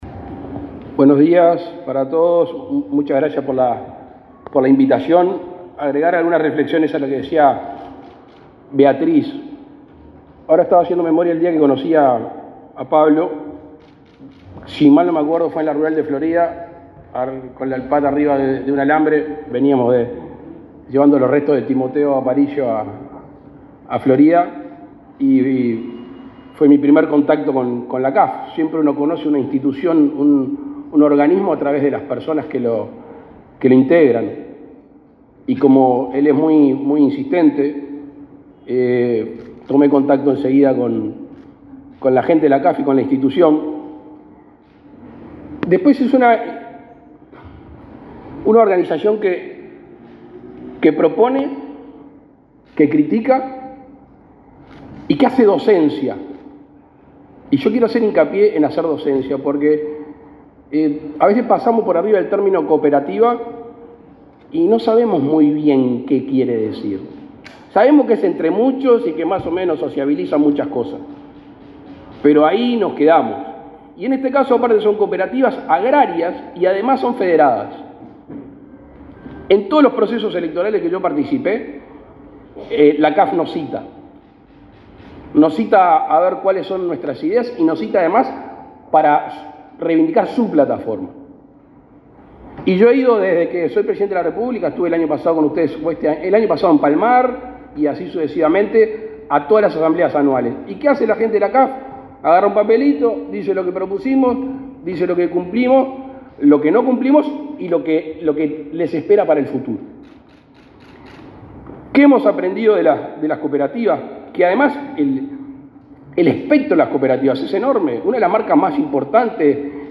Palabras del presidente Luis Lacalle Pou
Palabras del presidente Luis Lacalle Pou 09/04/2024 Compartir Facebook Twitter Copiar enlace WhatsApp LinkedIn El presidente de la República, Luis Lacalle Pou, encabezó, este martes 9 en el Palacio Legislativo, la celebración del 40.° aniversario de las Cooperativas Agrarias Federadas.